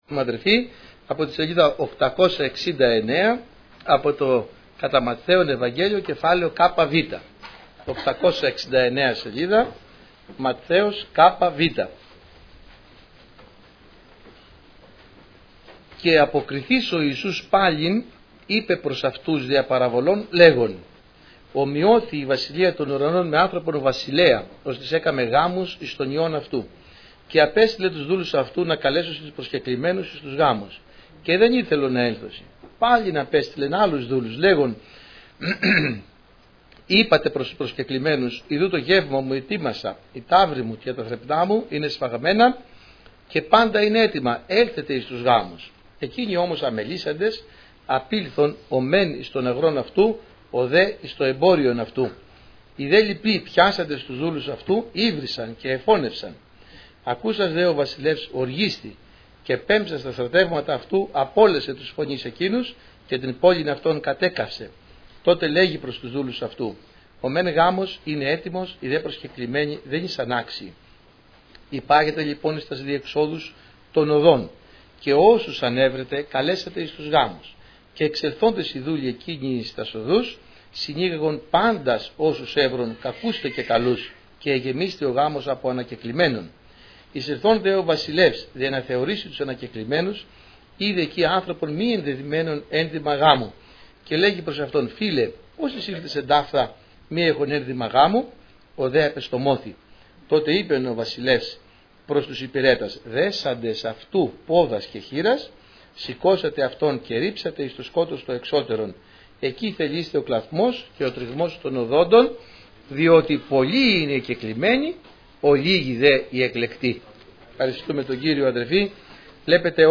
Κυριακάτικα Ημερομηνία